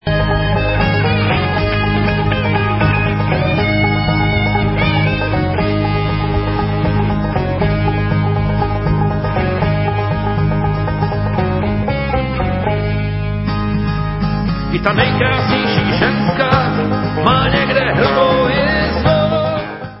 Reedice alba ze zlatého fondu naší bluegrassové muziky!